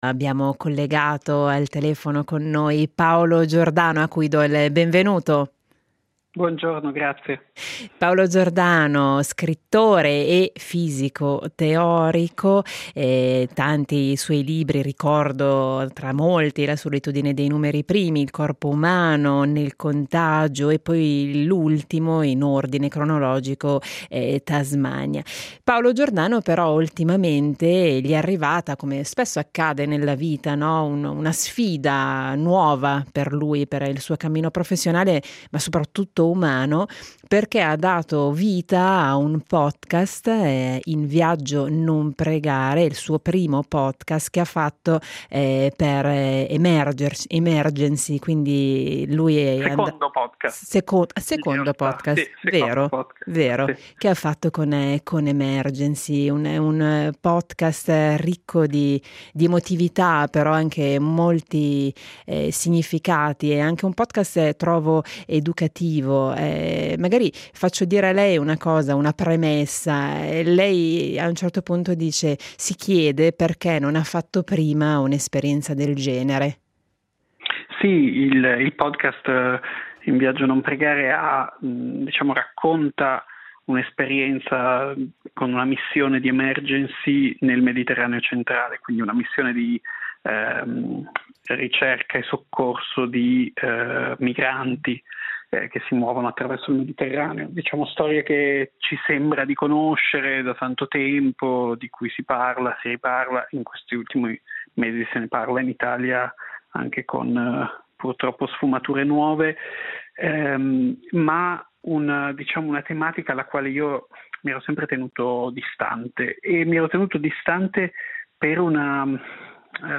Intervista a Paolo Giordano